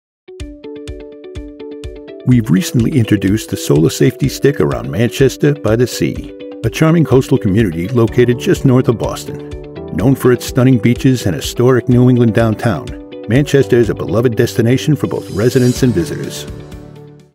I have a deep, rich, resonant voice perfect for any type of voice over you need.
Parking meter training video Friendly, Informative, Boston accent
A Source-Connect equipped professional Whisper Room sound booth.